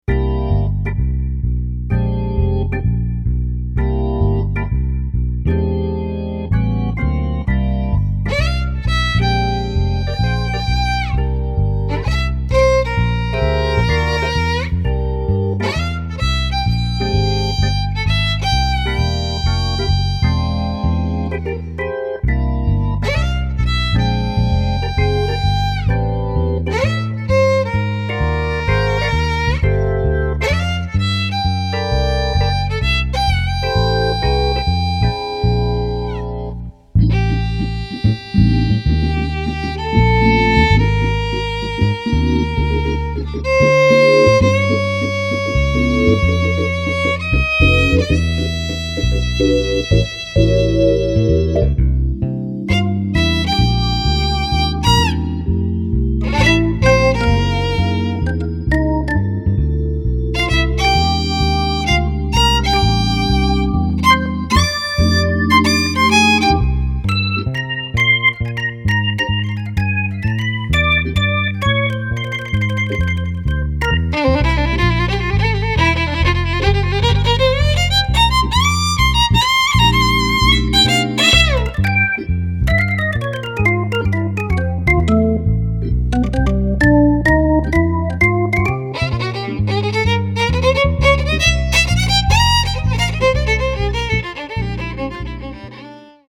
swing
organ sound with:
violin